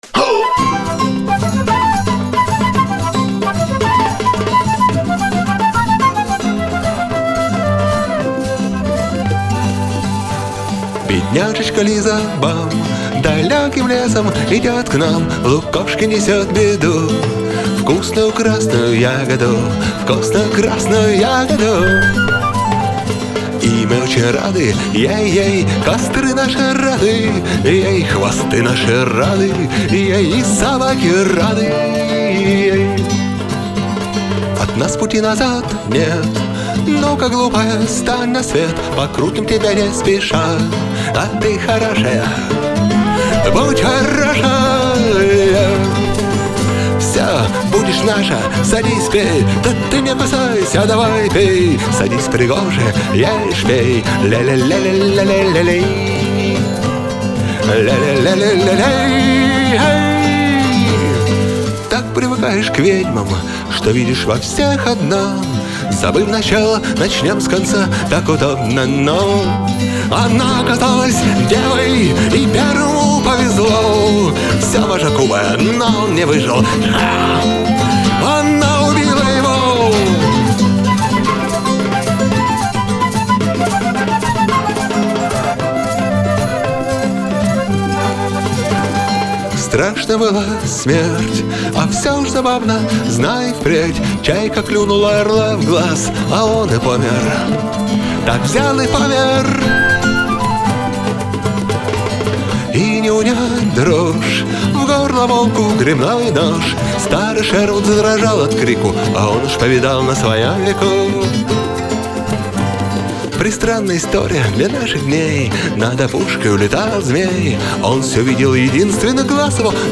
• Жанр: Альтернативная